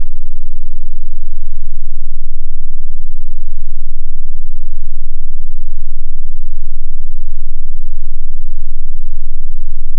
AudioCheck Tone Tests
Low Frequency Tones